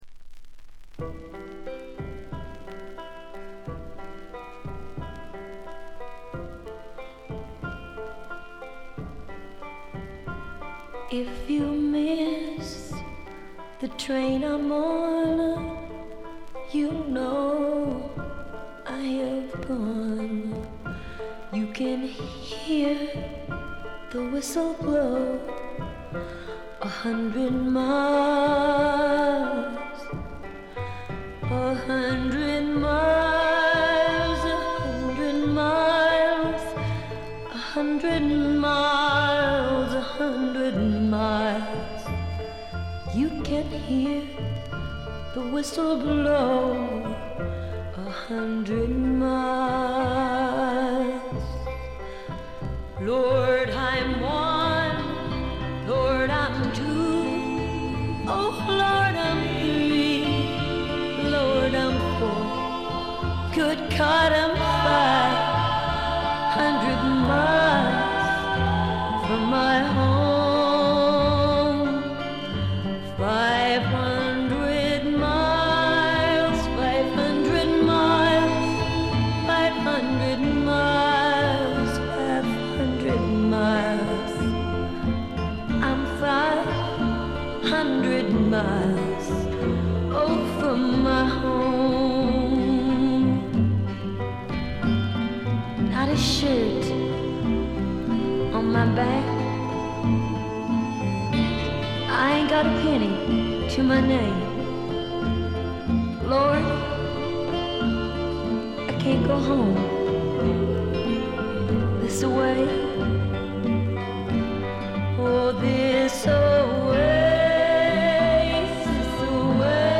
B1でプツ音数回。全体に細かなチリプチ、バックグラウンドノイズが出ていますが気になるのはこのB1ぐらい。
美しいフォーク・アルバムです。
最初期のモノラル盤。
試聴曲は現品からの取り込み音源です。